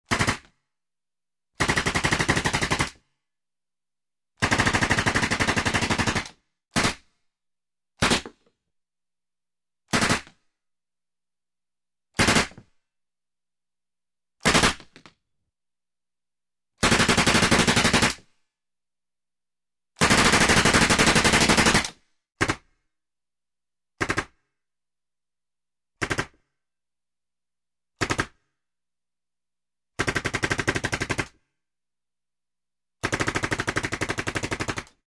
Звуки огнестрельного оружия
Выстрелы из автомата Узи